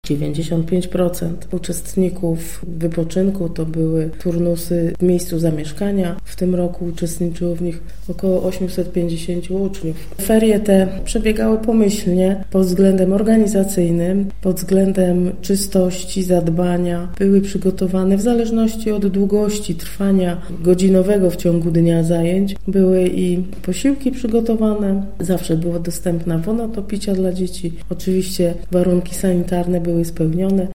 Nie stwierdziliśmy uchybień – mówi Dorota Baranowska, Państwowy Powiatowy Inspektor Sanitarny w Zielonej Górze: